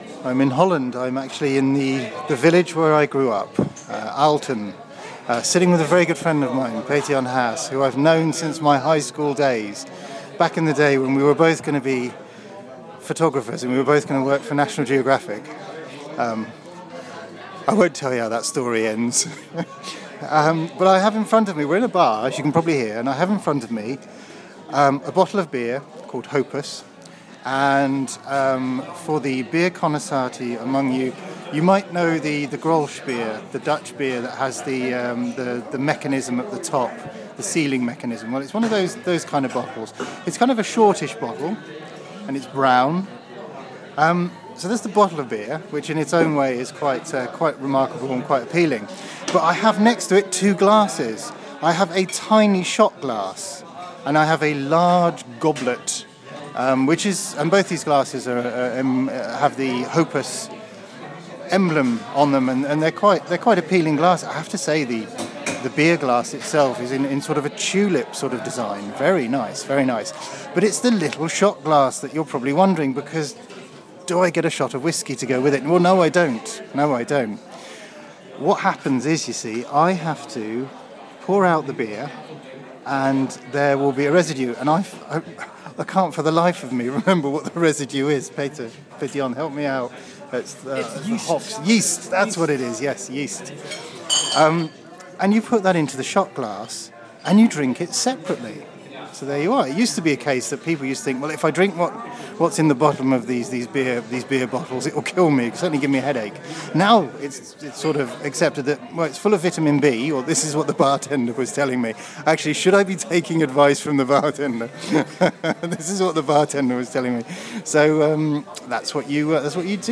At Cafe Schiller in Aalten, the Netherlands